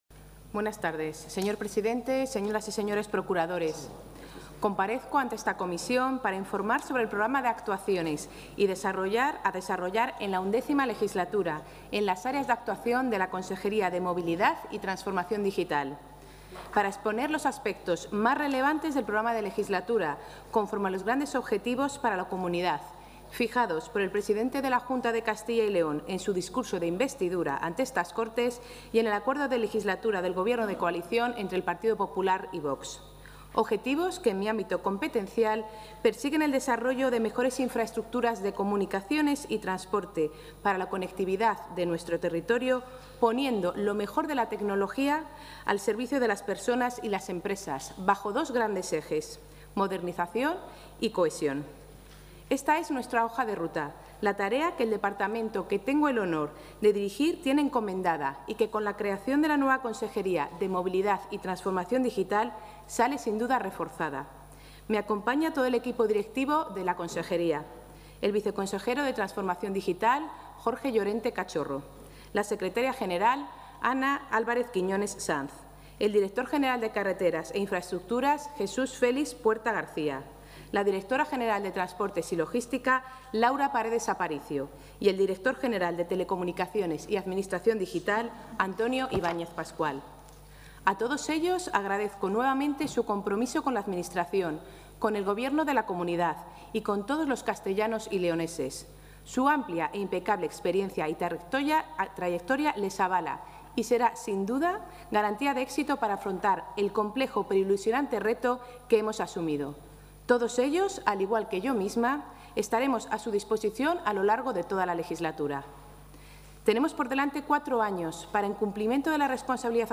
Comparecencia consejera Movilidad y Transformación Digital.
La consejera de Movilidad y Transformación Digital, María González Corral, ha comparecido hoy en las Cortes para detallar su programa para la XI Legislatura, con el objetivo primordial de impulsar la modernización, innovación y mejora de las conexiones terrestres y digitales, como garantía de desarrollo y competitividad de los ciudadanos y empresas de Castilla y León.